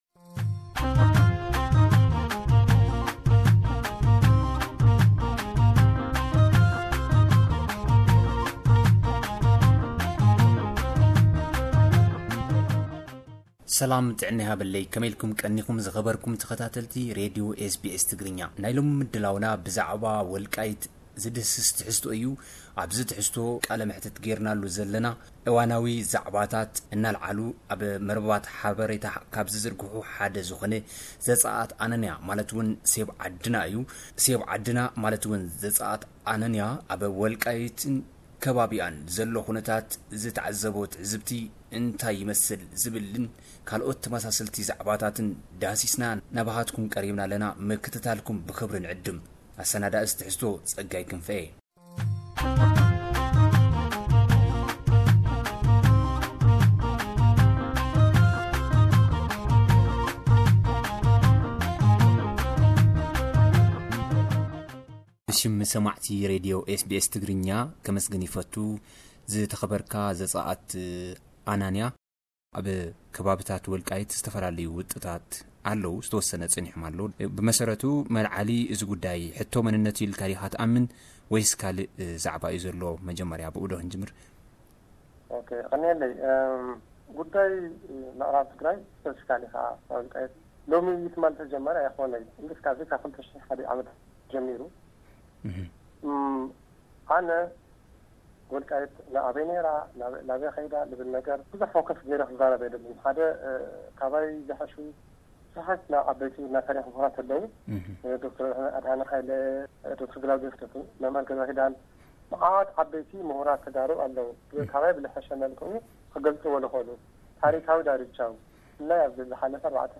ቃለ መሕትት